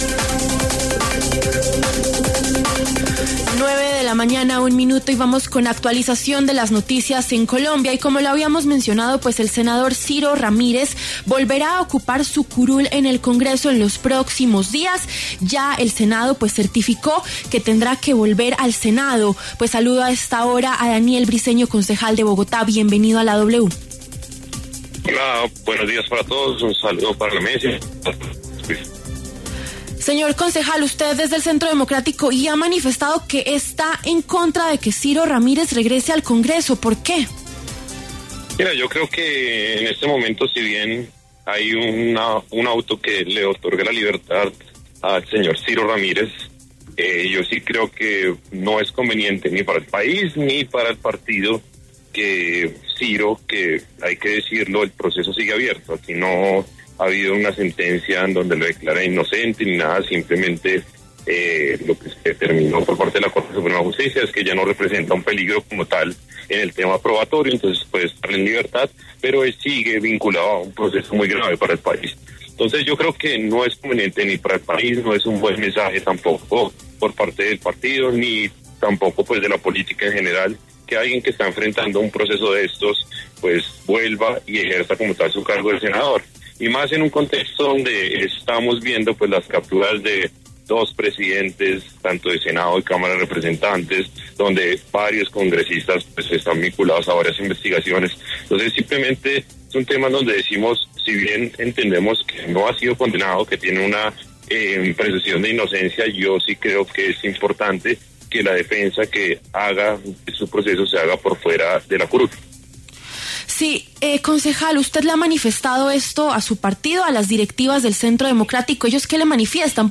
Por este motivo, en los micrófonos de W Fin De Semana conversó Daniel Briceño, concejal del Centro Democrático, sobre la coyuntura.